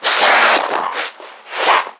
NPC_Creatures_Vocalisations_Infected [95].wav